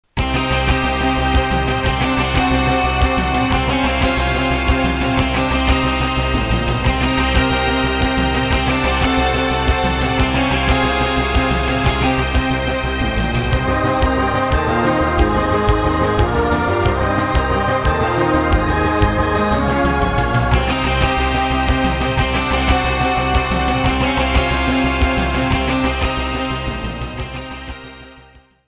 お遊びなので、パート数も最低限で、音色やボリュームも適当です。
（ヘッドホンで音量調整しているのでスピーカーで聴くと低音が小さいはずです・・・）
030 　ゲーム風 緊迫した感じ（Cm） 07/10/31